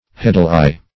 Search Result for " heddle-eye" : The Collaborative International Dictionary of English v.0.48: Heddle-eye \Hed"dle-eye`\, n. (Weaving) The eye or loop formed in each heddle to receive a warp thread.